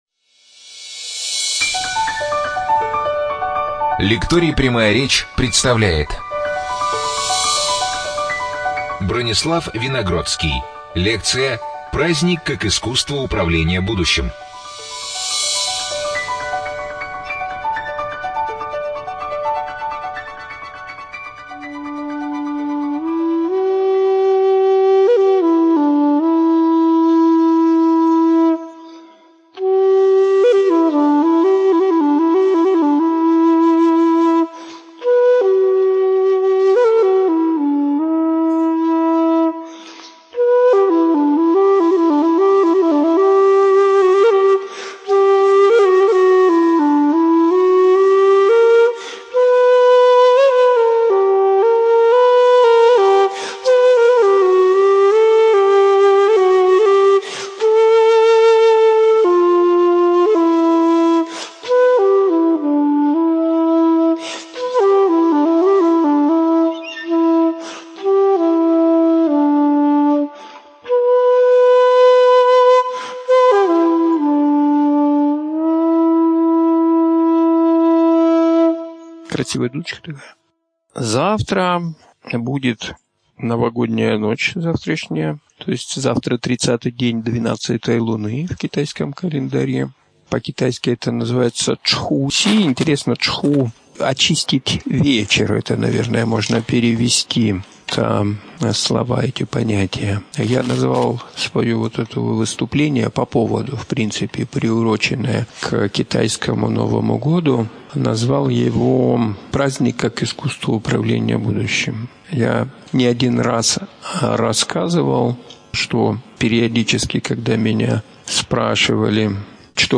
ЧитаетАвтор
Студия звукозаписиЛекторий "Прямая речь"